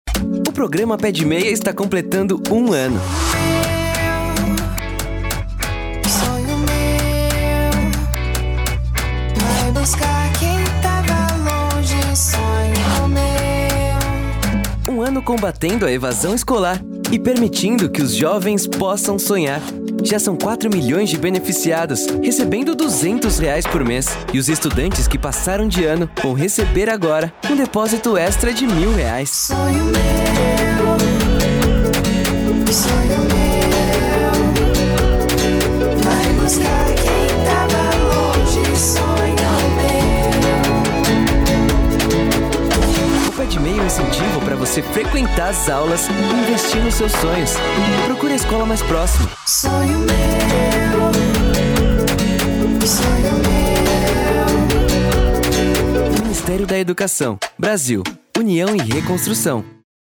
SPOT MEC - Pé-de-meia 2025